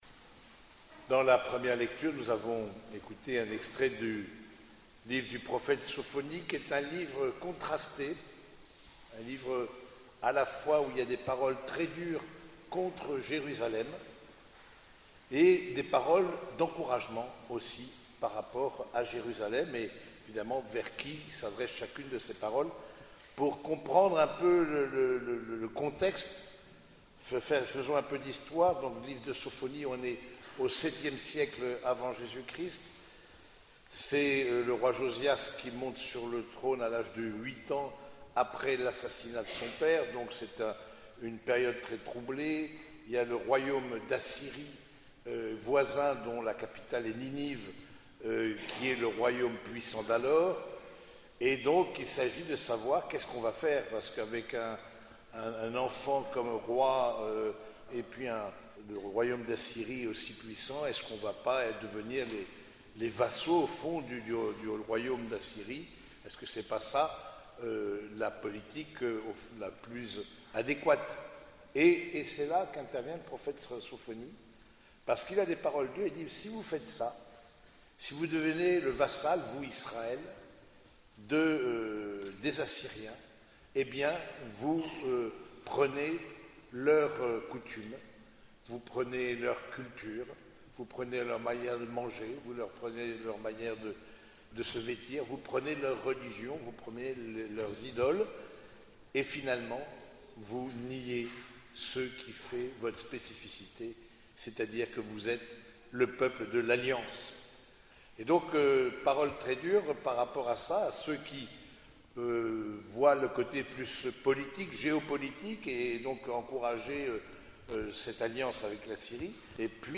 Homélie du 4e dimanche du Temps Ordinaire